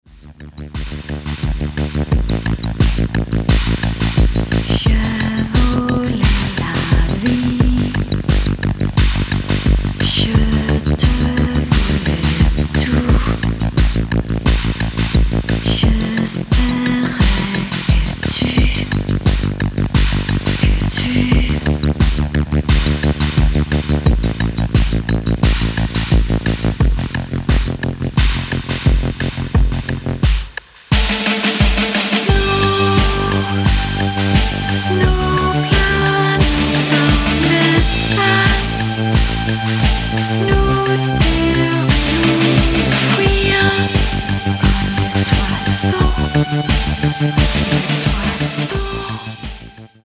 analoge Keyboards, Synthies und Effektgeräte
klassischem 60s Frauengesang